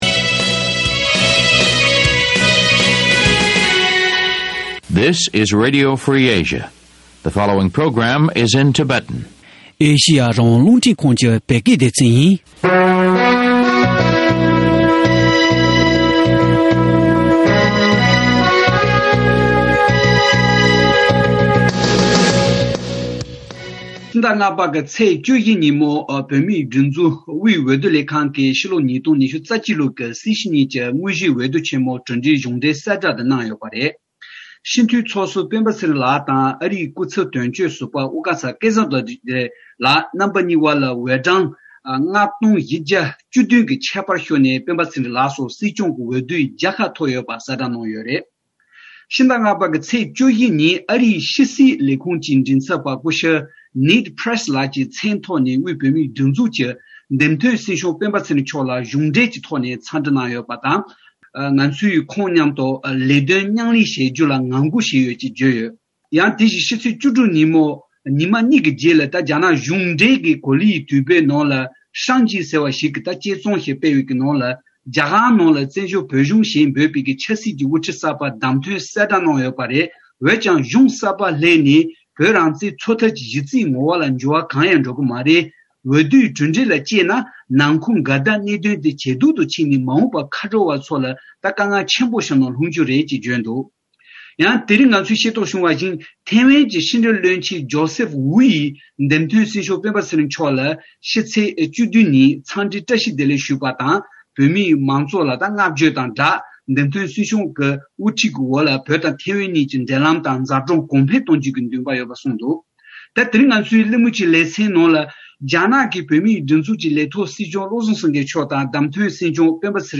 བཅའ་འདྲི་བྱེད་པ་གསན་རོགས་གནང་།